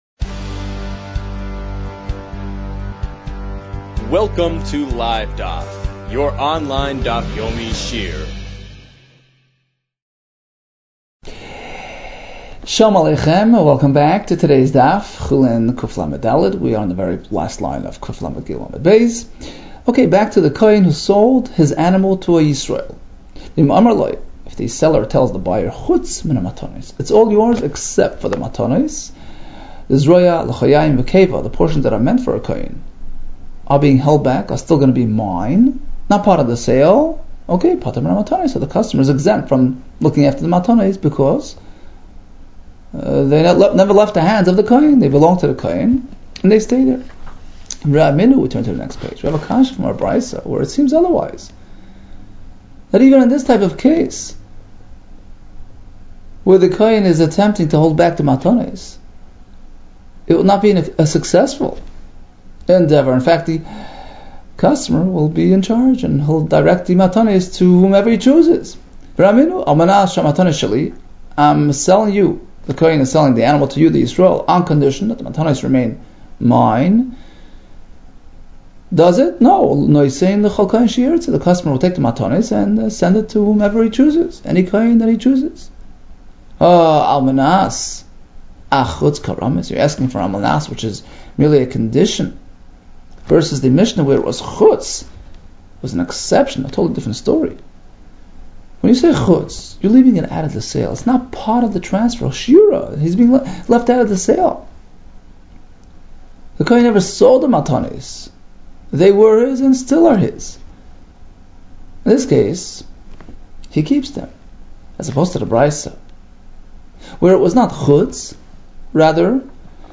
Chulin 134 - חולין קלד | Daf Yomi Online Shiur | Livedaf